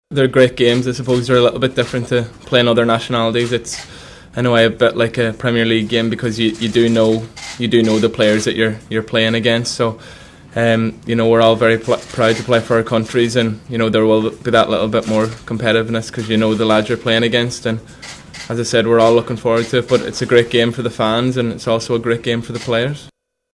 Captain Seamus Coleman says playing Wales has the feeling of a local derby…